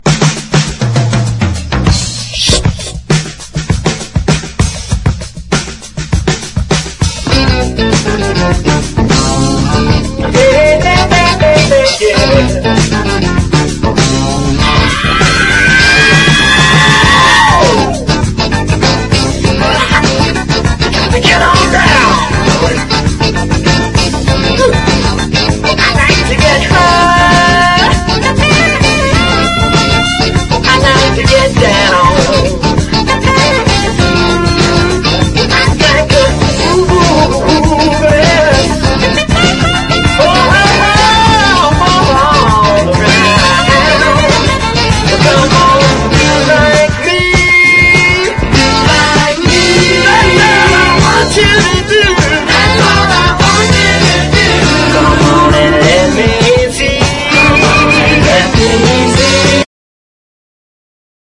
NEW ZEALAND WAVY POP / ELE POP！
ピコピコしたシンセ・サウンドとエキセントリックなサビが◎なヒットした
伸びやかなメロディーとウェイヴィーなキーボード・アレンジ、コーラスもきれいにハマった
小気味よく駆け抜けるビートに心躍る